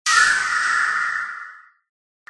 ghost2.ogg